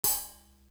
Book Of Rhymes Open Hat .wav